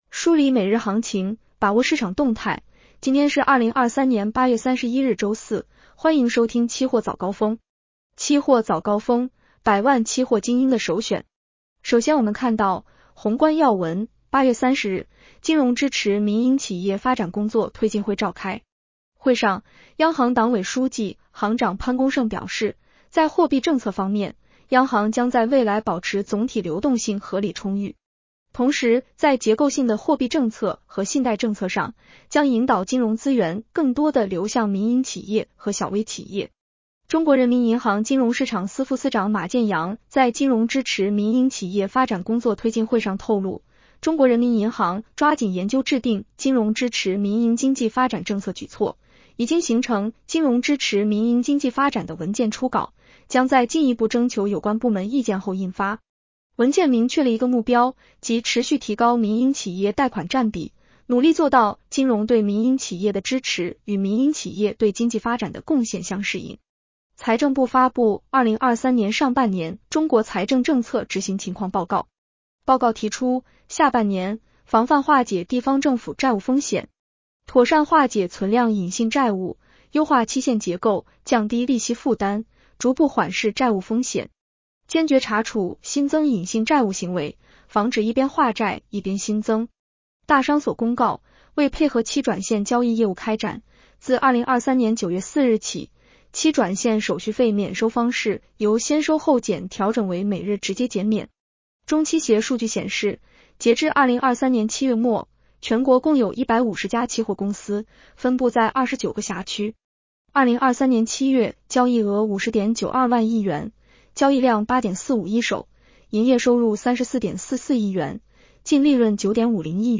【期货早高峰-音频版】 女声普通话版 下载mp3 宏观要闻 1. 8月30日，金融支持民营企业发展工作推进会召开。